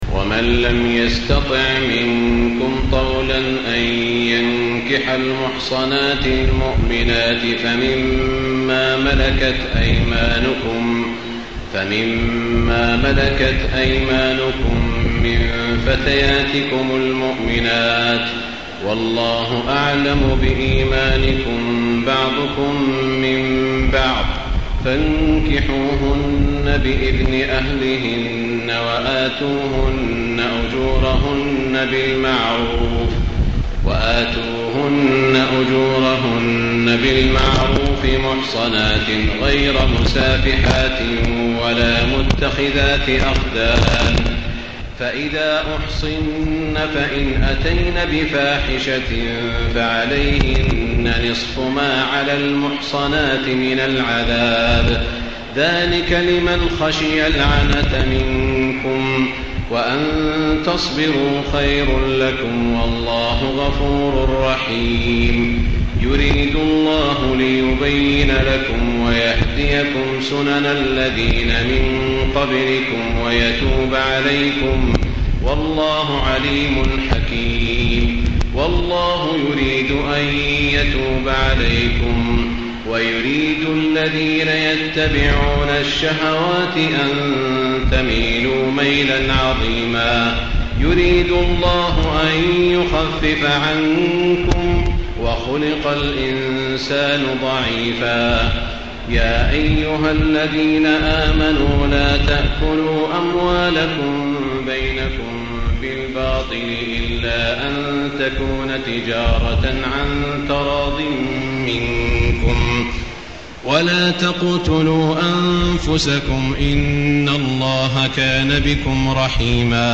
تهجد ليلة 25 رمضان 1435هـ من سورة النساء (25-99) Tahajjud 25 st night Ramadan 1435H from Surah An-Nisaa > تراويح الحرم المكي عام 1435 🕋 > التراويح - تلاوات الحرمين